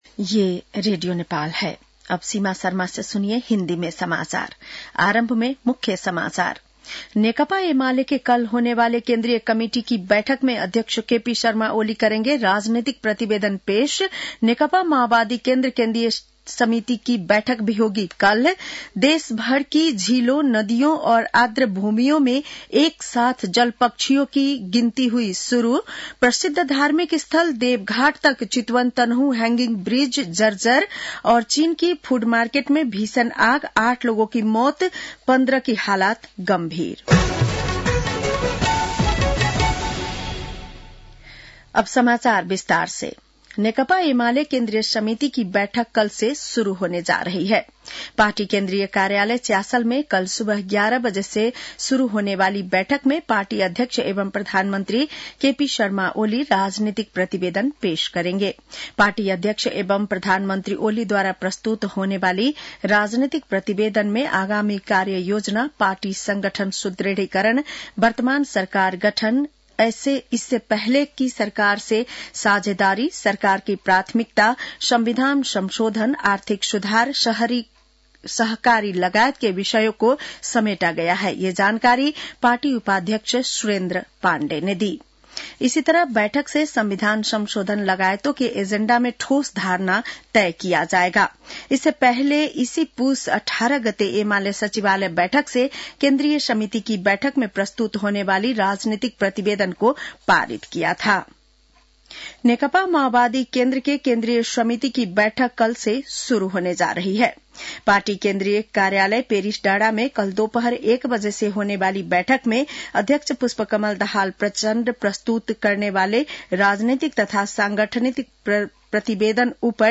बेलुकी १० बजेको हिन्दी समाचार : २१ पुष , २०८१
10-PM-Hindi-News-9-20.mp3